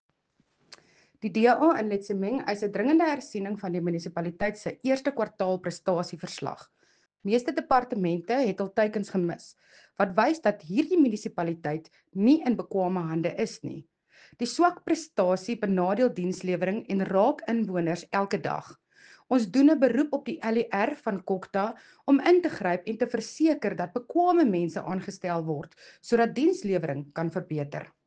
Afrikaans soundbite by Cllr Mariska Potgieter and